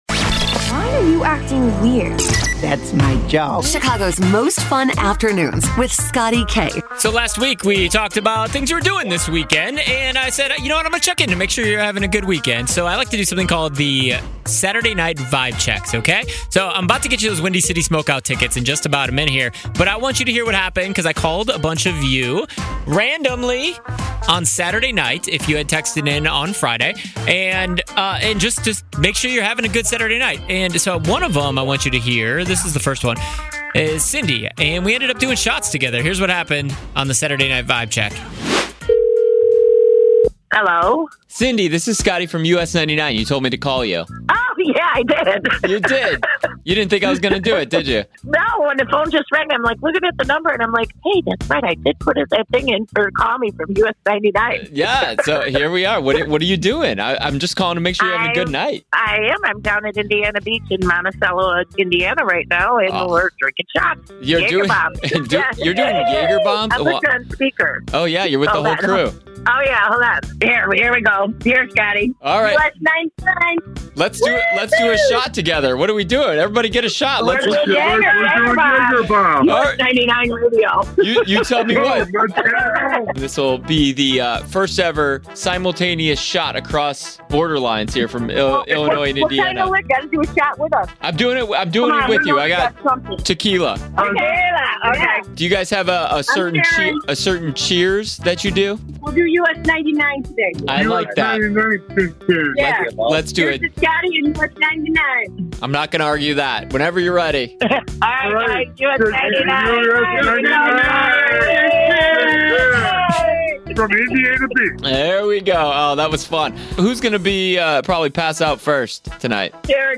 SATURDAY NIGHT VIBE CHECK - Randomly Calling Listeners